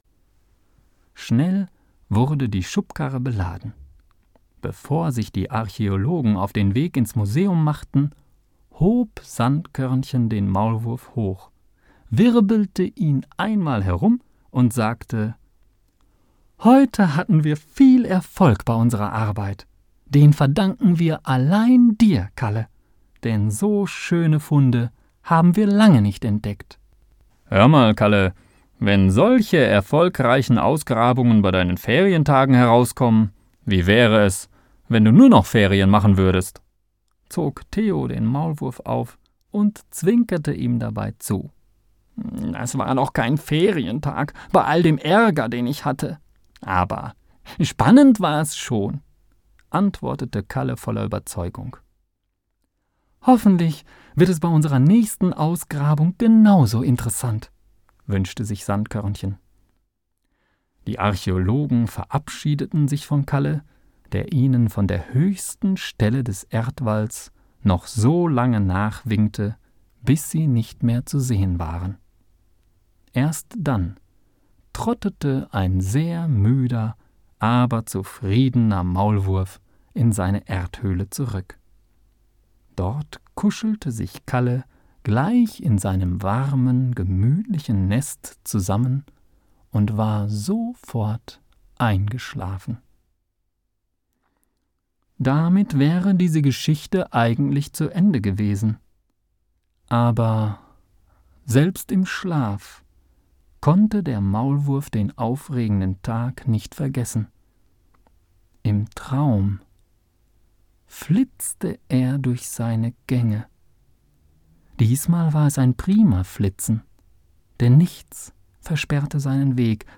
Lies mir bitte das Kapitel vor...